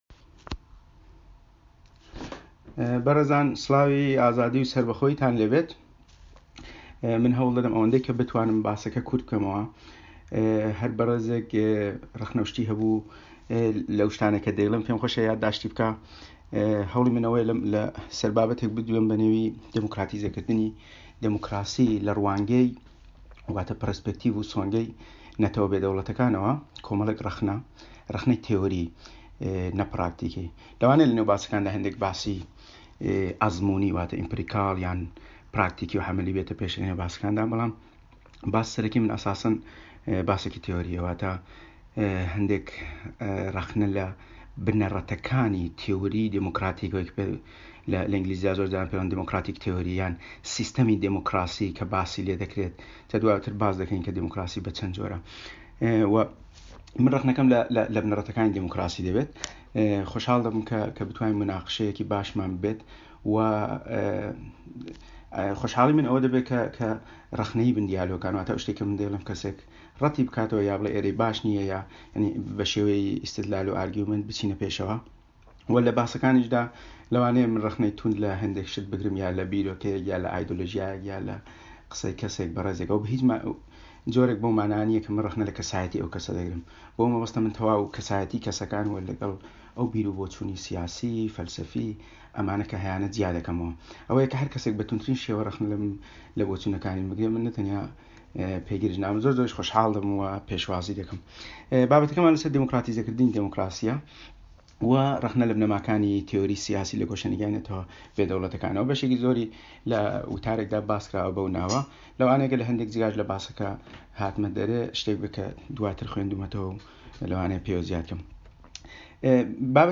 کڵابهاوس.